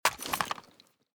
mossberg_draw.ogg